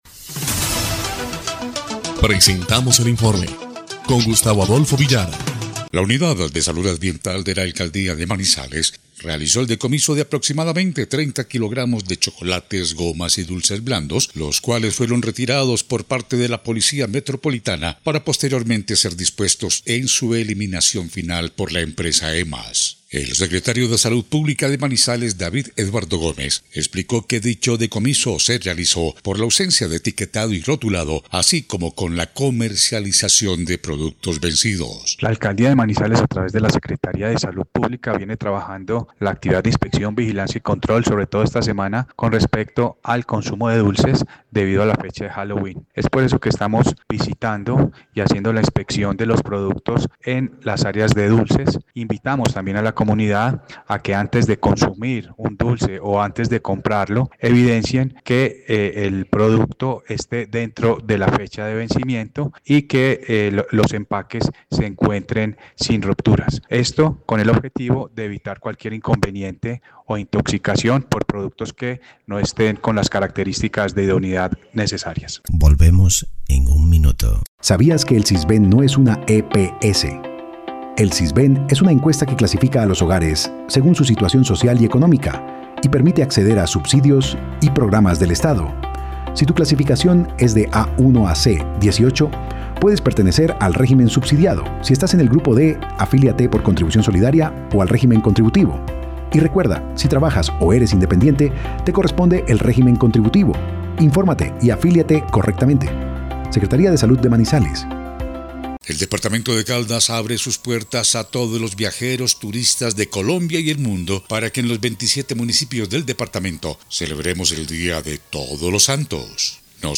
EL INFORME 1° Clip de Noticias del 31 de octubre de 2025